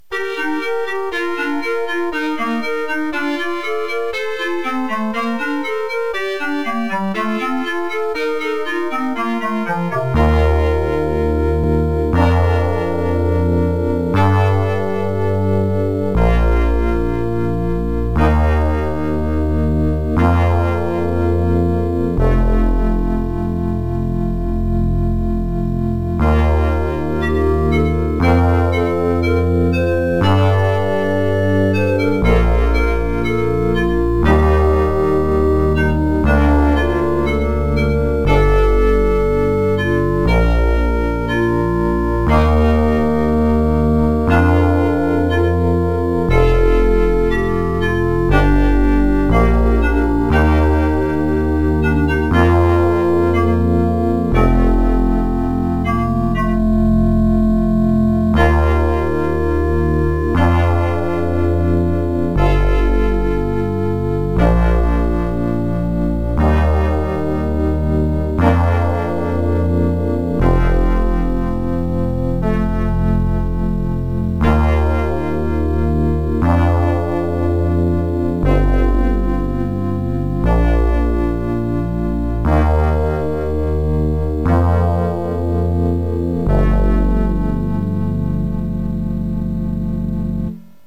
This is a slow and soft song that I made using an old computer which has a soundcard that features the OPL3 FM synth chip.
AMBIENT MUSIC; SYNTHESIZER MUSIC